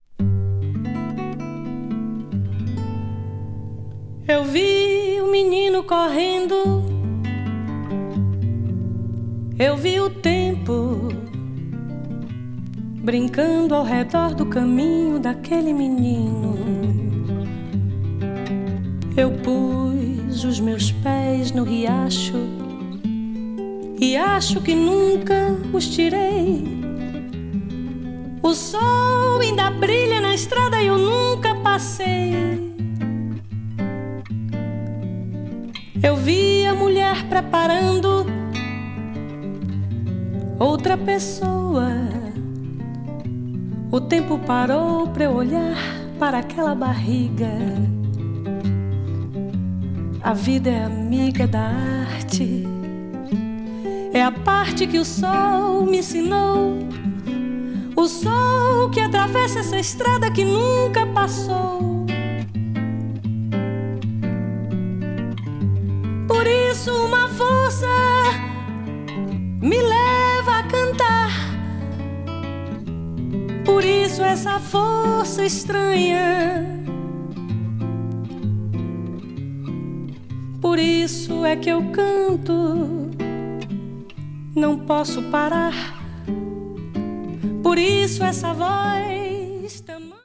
one of the leading female singers in the Brazilian MPB scene
an acoustic tune
brazil   mpb   south america   tropical   world music